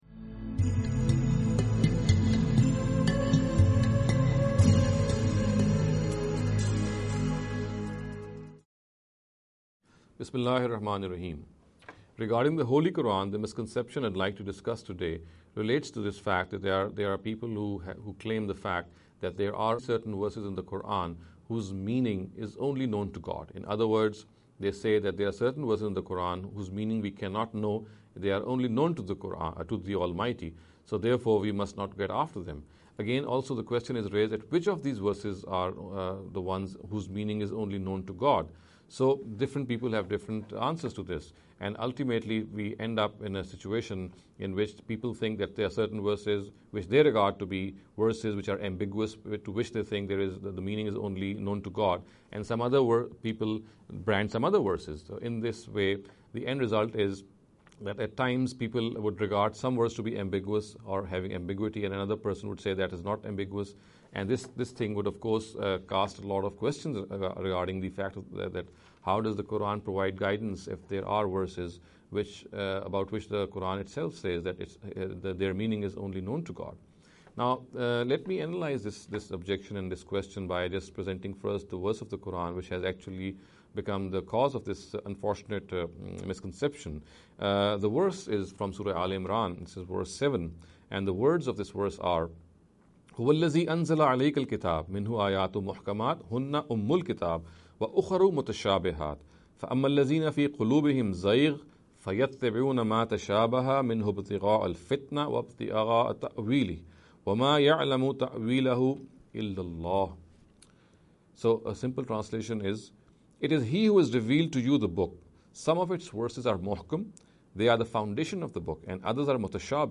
This lecture series will deal with some misconception regarding the Holy Qur’an.